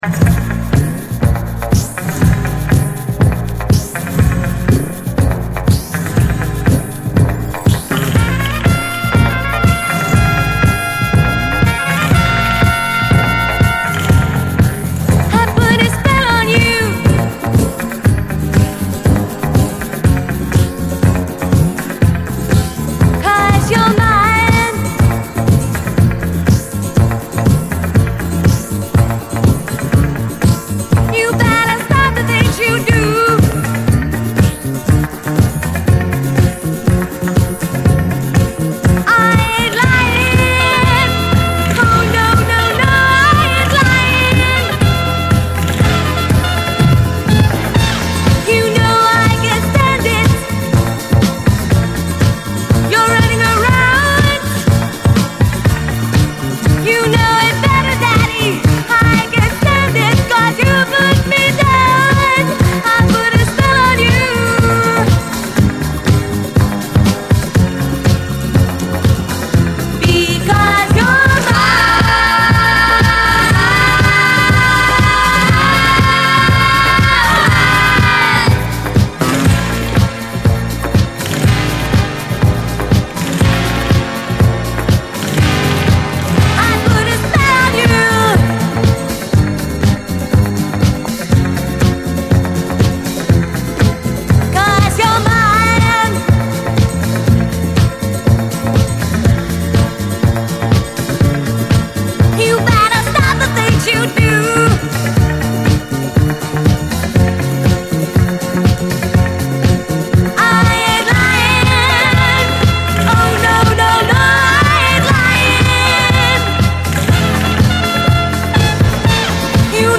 DISCO
オランダ産コケティッシュ・ロッキン・ラテン・ディスコ！
スパニッシュでロッキンなノリがカッコいい！スパニッシュなギターの刻みがイカしてる